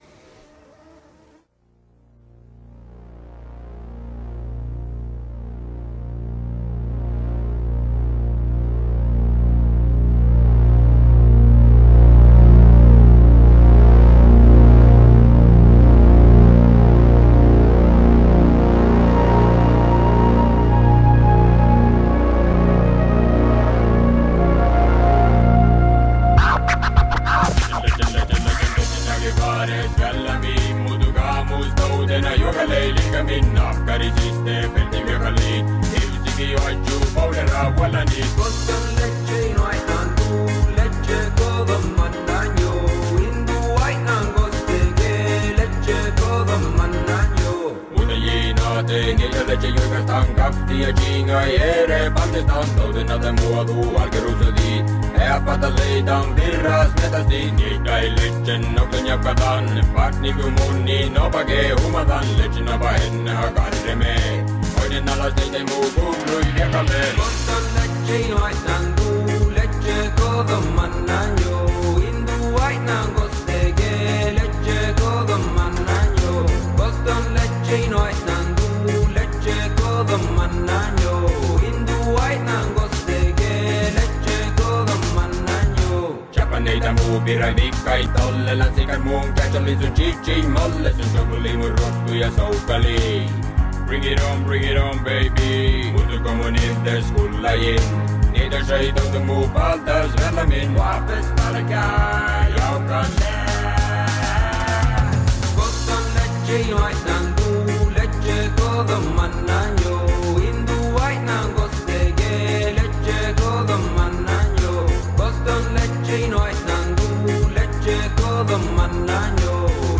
SAMI RAP - i once heard this on Swedish radio